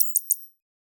Knock Notification 7.wav